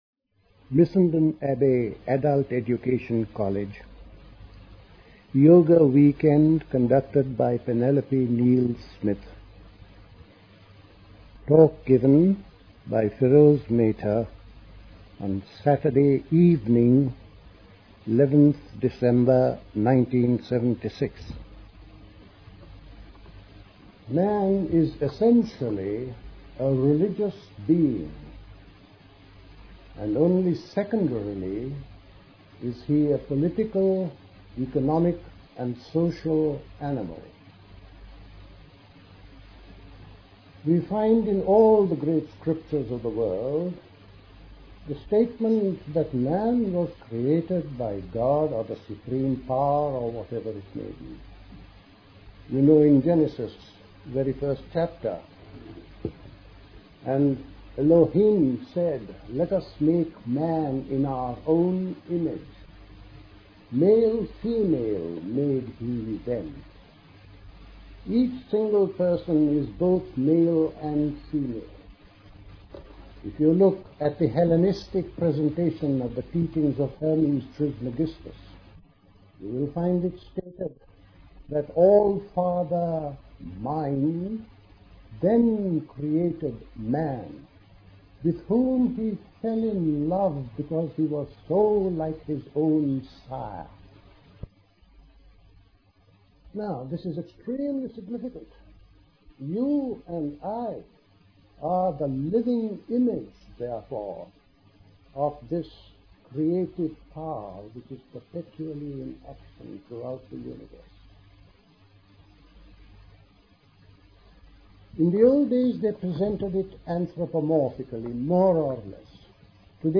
A talk
at Missenden Abbey, Great Missenden, Buckinghamshire on 11th December 1976